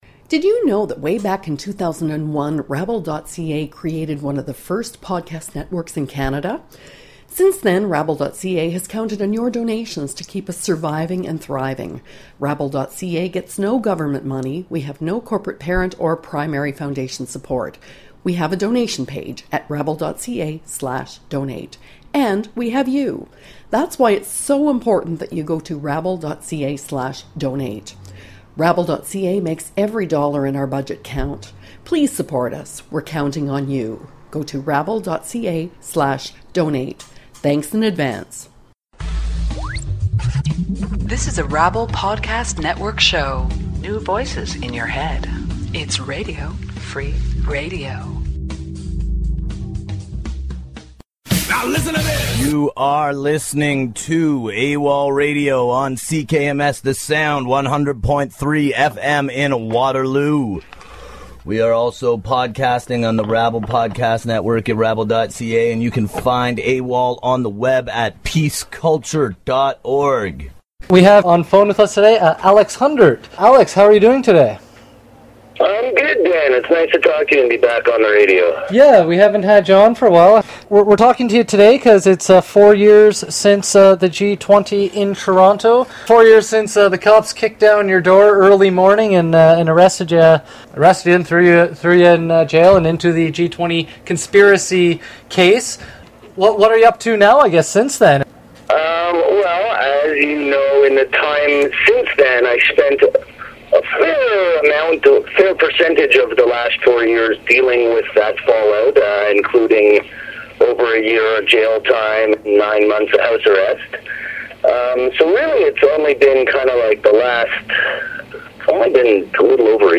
G20 Four Years Later: Interview